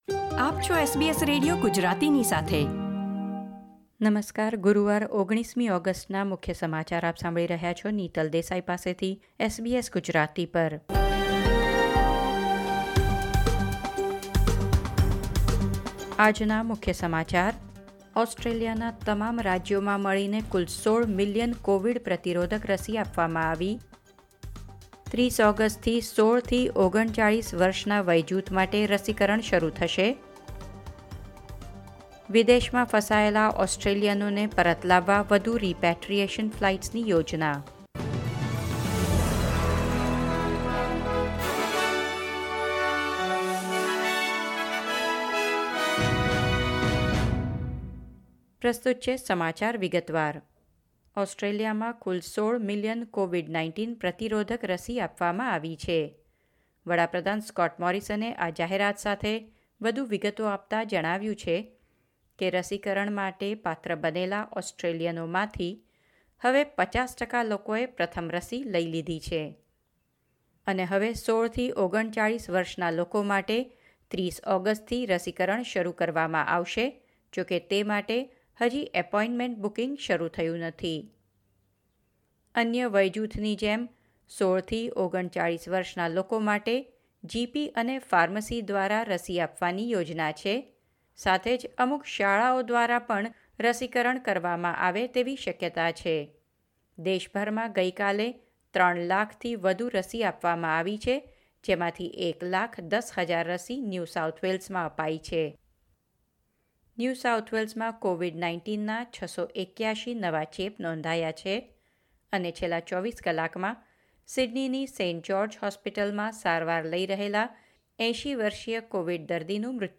SBS Gujarati News Bulletin 19 August 2021